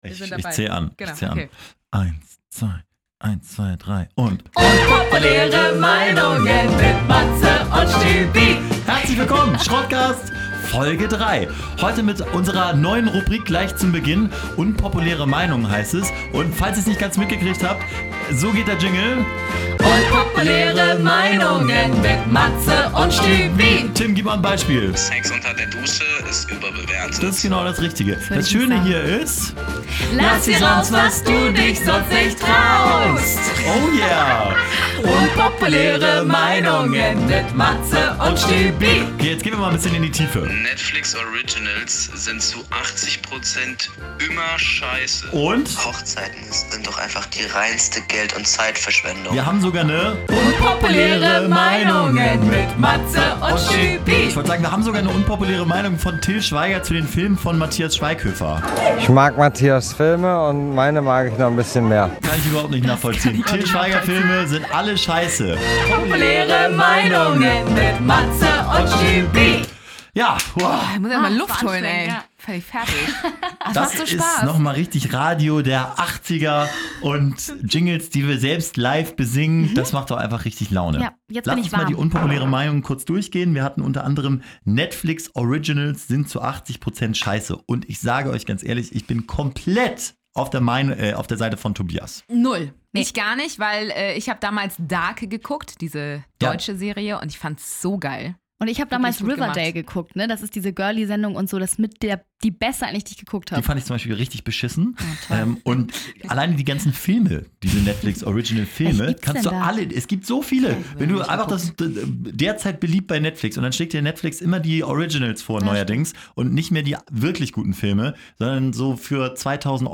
In unserer neuen Rubrik "unpopuläre Meinungen" wird sogar gesungen. Diesmal in den Top 3: Überbewertete Dinge.